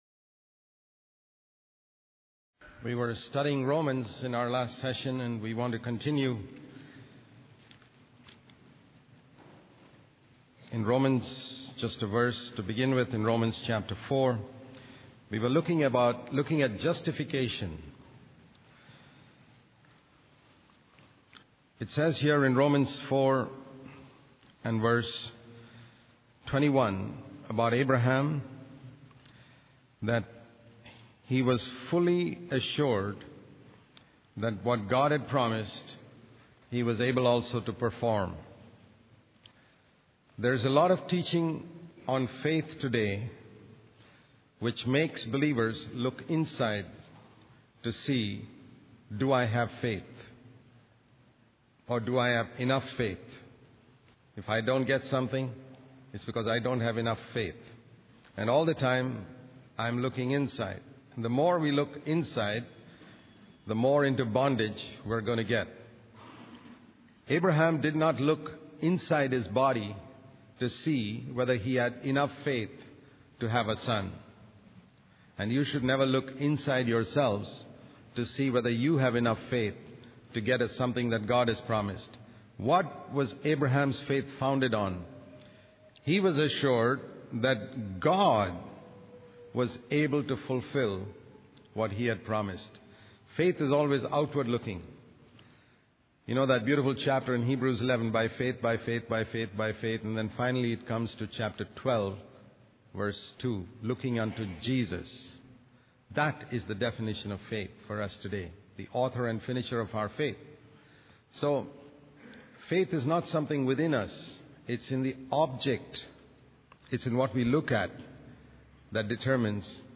In this sermon, the speaker focuses on Romans Chapter 7 and the concept of being delivered from the law. He uses the analogy of marriage to explain how instead of being married to Christ, some people get married to the law. The speaker discusses the struggle of wanting to live a holy life but constantly slipping up in areas where one desires to do better.